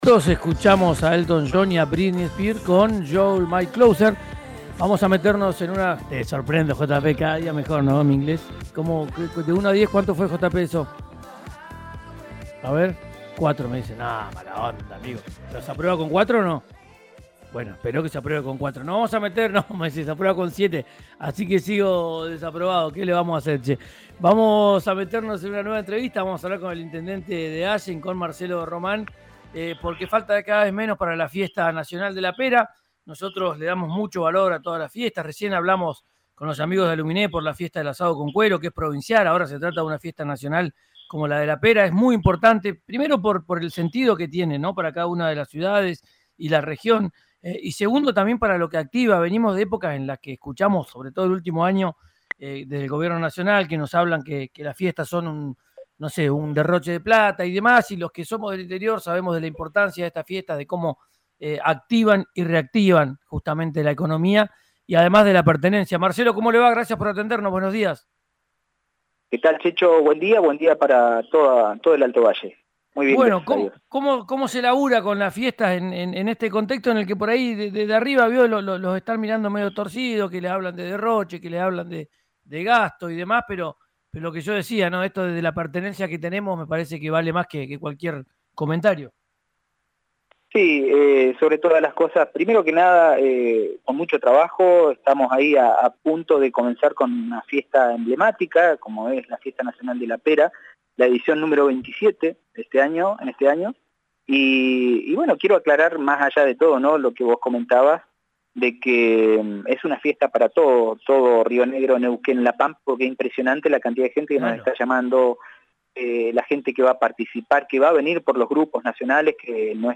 Escuchá a Marcelo Román, intendente de Allen, en RÍO NEGRO RADIO: